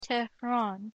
/ˈtɛ.ɹæn(英国英語), ˌteˈrɑn(米国英語)/